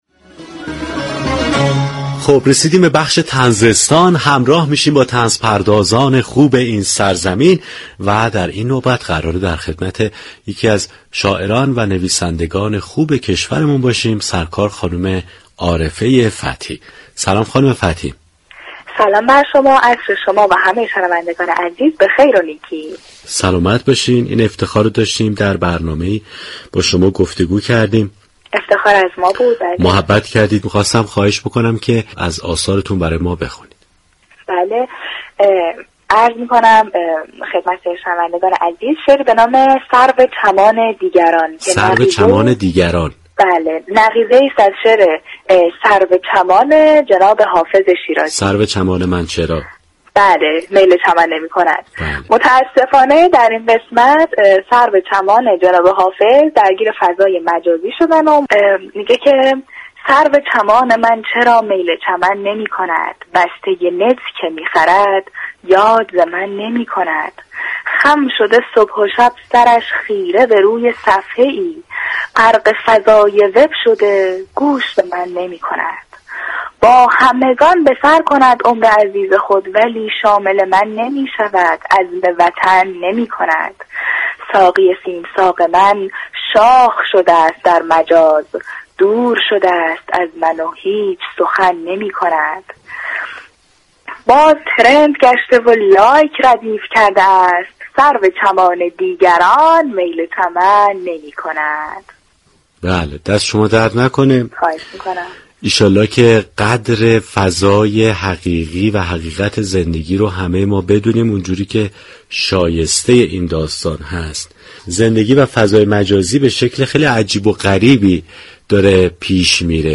شنونده گفتگوی